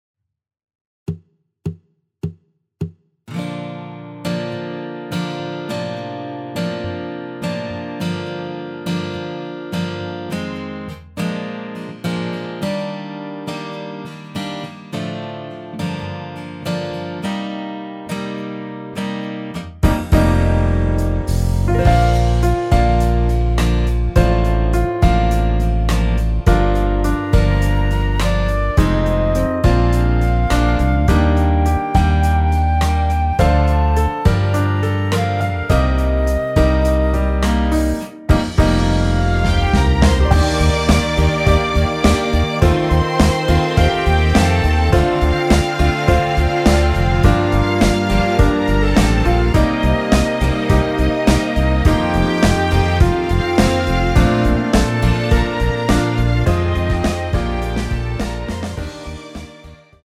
Bb
앞부분30초, 뒷부분30초씩 편집해서 올려 드리고 있습니다.
중간에 음이 끈어지고 다시 나오는 이유는
위처럼 미리듣기를 만들어서 그렇습니다.